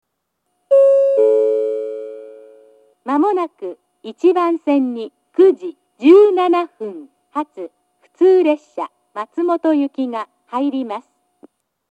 接近放送、発車メロディーと中央制御型の放送は別々のスピーカーから流れます。
１番線接近予告放送 09:17発普通松本行の放送です。
接近放送の流れる前に流れます。